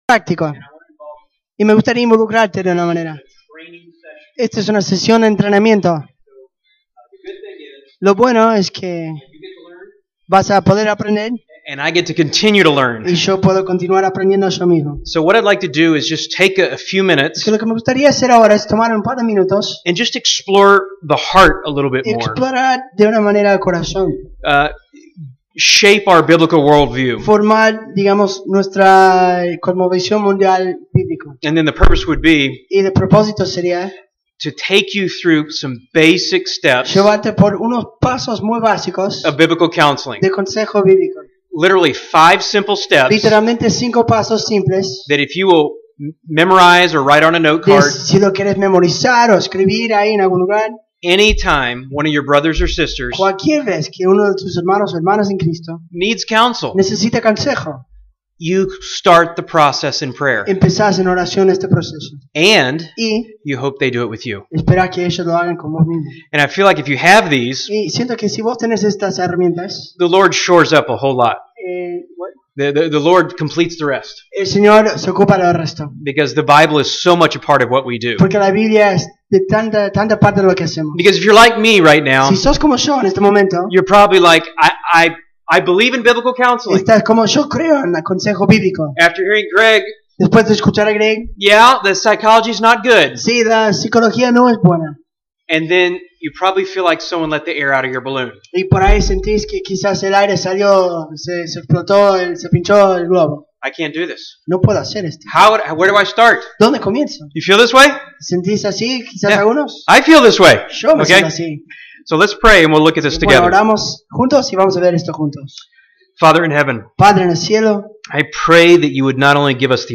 Serie: Conferencia Consejería Bíblica 2014
septiembre 5, 2014 Sermón ¿Cómo aconsejo bíblicamente?